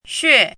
怎么读
xuè xiě
xue4.mp3